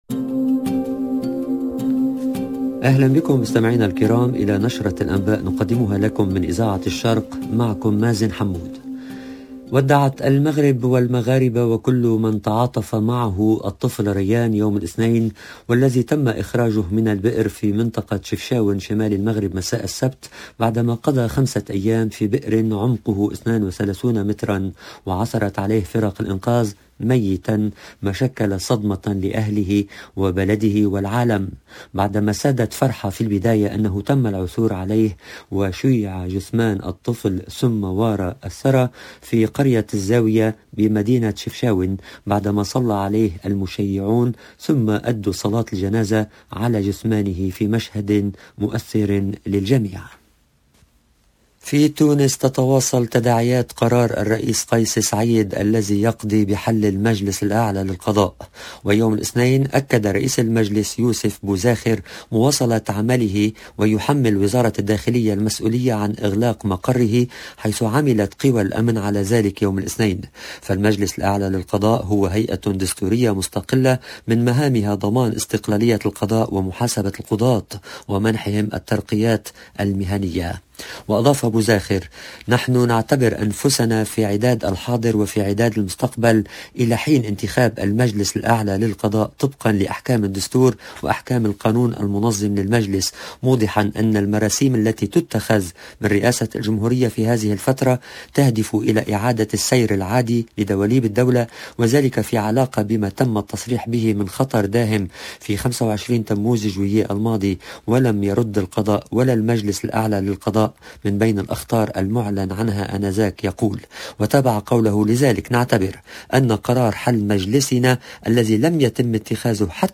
LE JOURNAL DU SOIR EN LANGUE ARABE DU 7/02/22